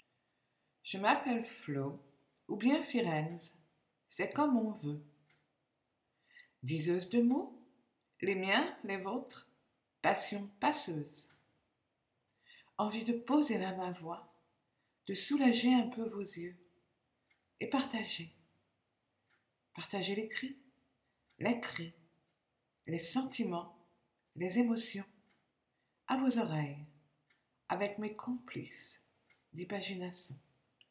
Touchantes ces vibrations de voix qui ondulent tantôt guillerettes et rieuses, tantôt rocailleuses ou ténébreuses…